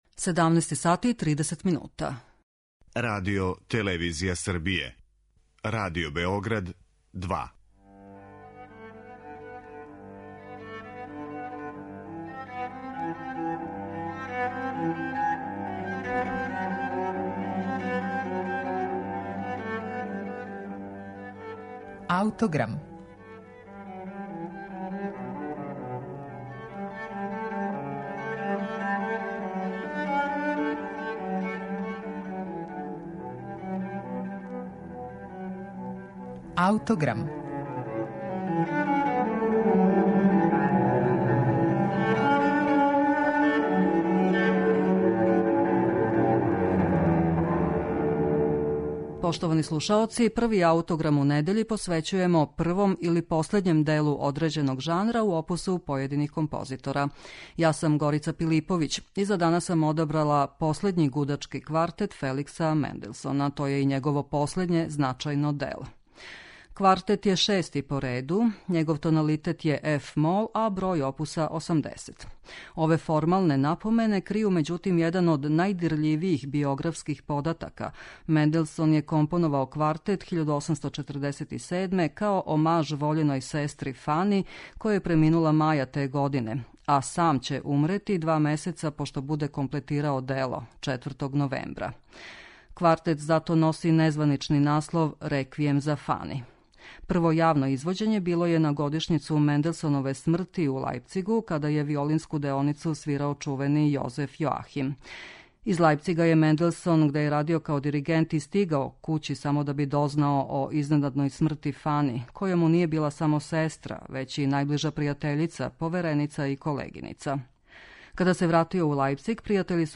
последњи гудачки квартет
Квартет је шести по реду, његов тоналитет је еф-мол, а број опуса 80.